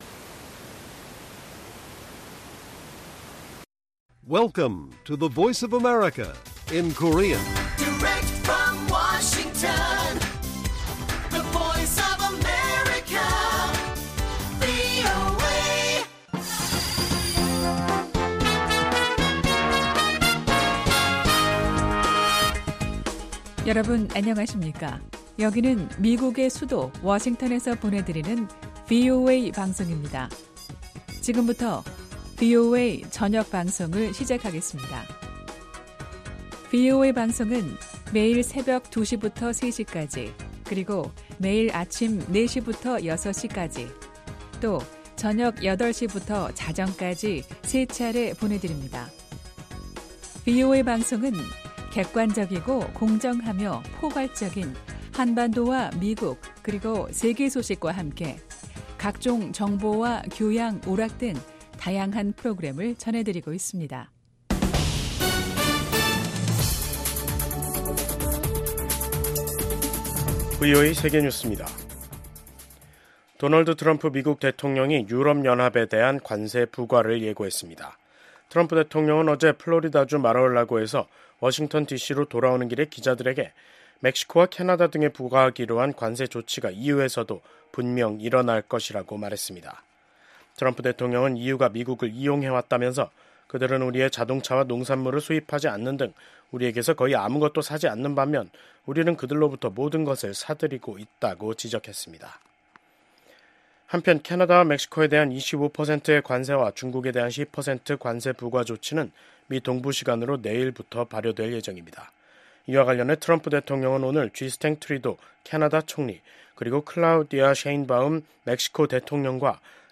VOA 한국어 간판 뉴스 프로그램 '뉴스 투데이', 2025년 2월 3일 1부 방송입니다. 마르코 루비오 국무장관이 미국이 세계 모든 문제를 짊어지는 현 상황을 비정상적이라고 평가하며 외교 초점을 ‘미국의 이익’에 맞출 것이라고 밝혔습니다. 루비오 국무장관이 불량국가라고 언급한 것에 대한 반응으로 북한은 도널드 트럼프 행정부 출범 이후 첫 대미 비난 담화를 냈습니다.